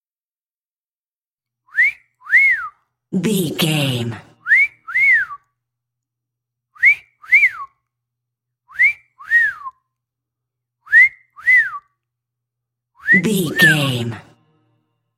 Whistle sexy x6
Sound Effects
urban